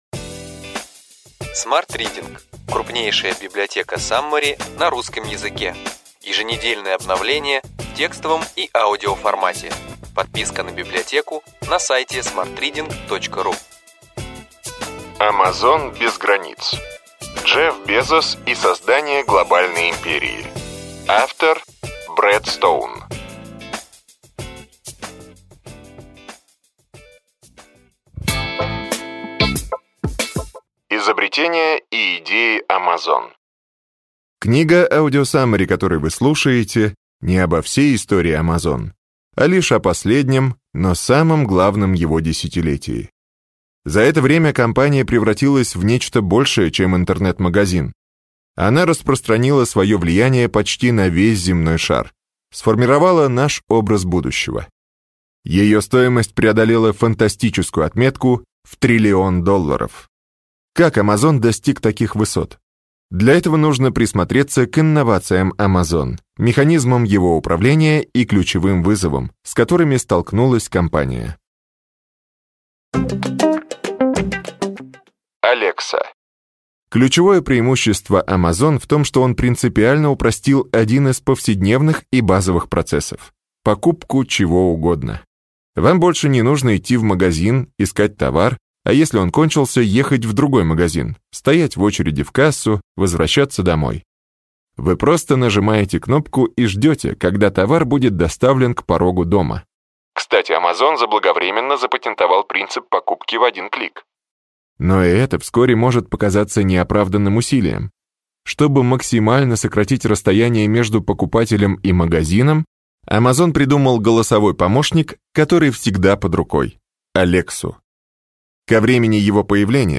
Аудиокнига Ключевые идеи книги: Amazon без границ. Джефф Безос и создание глобальной империи. Брэд Стоун | Библиотека аудиокниг